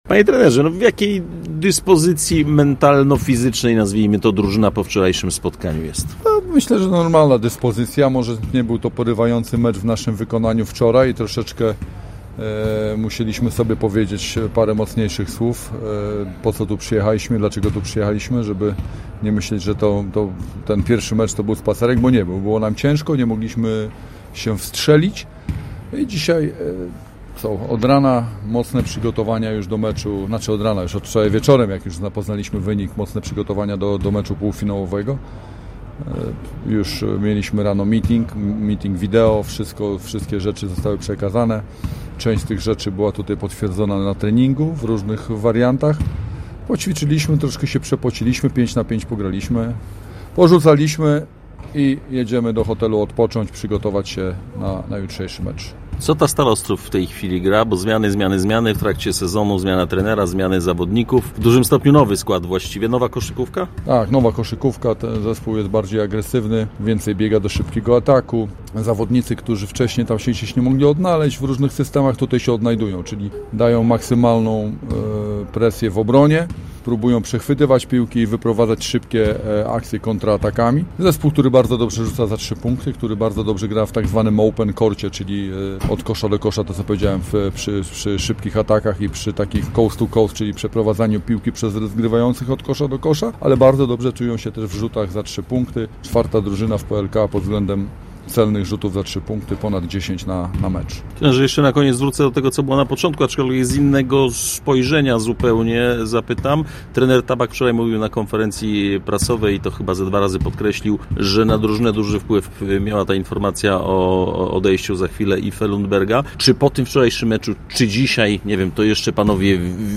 który w rozmowie z RZG w piątkowe popołudnie opowiadał również o półfinałowym przeciwniku: https